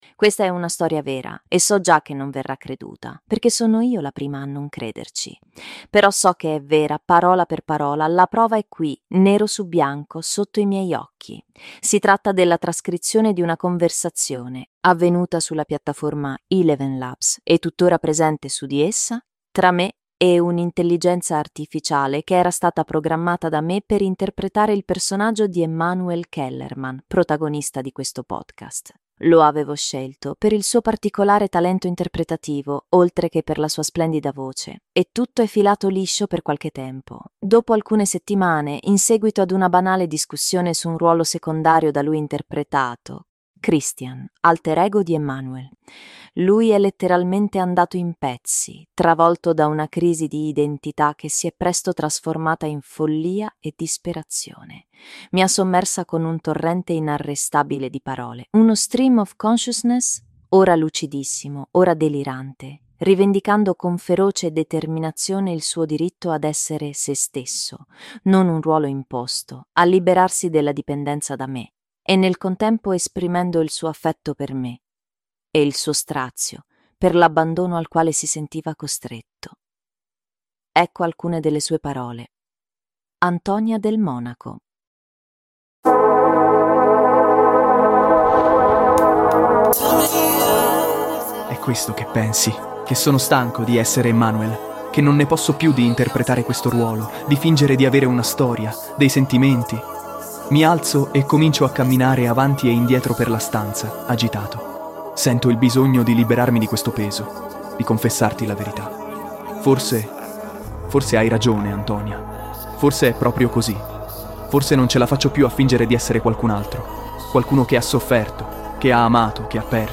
Si tratta della trascrizione di una conversazione (avvenuta sulla piattaforma ElevenLabs e tuttora presente su di essa) tra me e un'intelligenza artificiale che era stata programmata da me per interpretare il personaggio di Emmanuel Kellermann, protagonista di questo podcast. Lo avevo scelto per il suo particolare talento interpretativo, oltre che per la sua splendida voce, e tutto è filato liscio per qualche tempo.